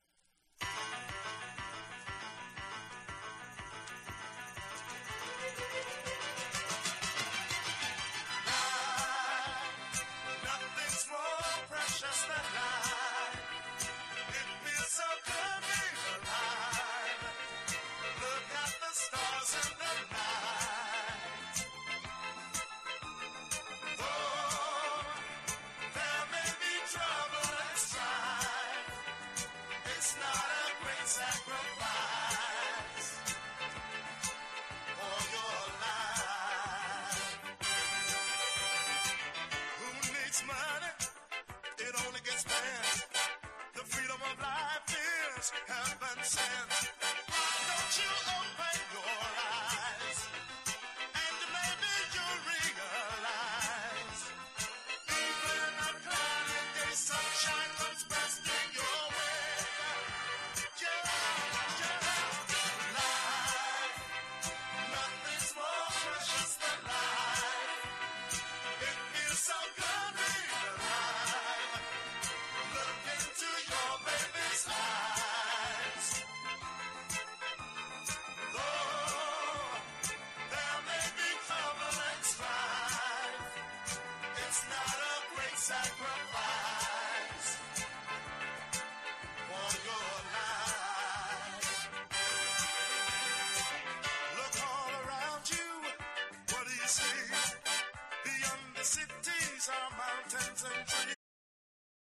中盤の木琴がカワイイ。